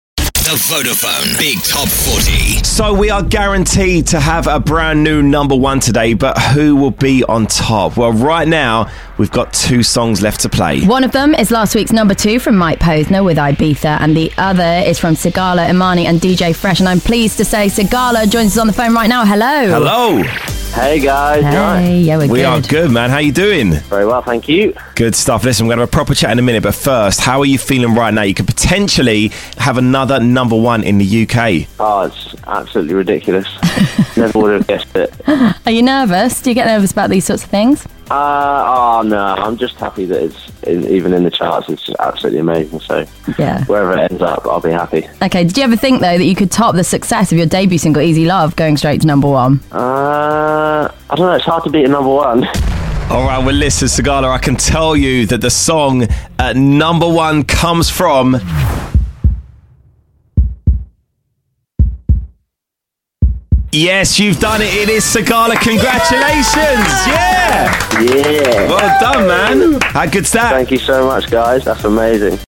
Superstar DJ Sigala had the chance to grab his second No.1 and he did it! Listen to his reaction here.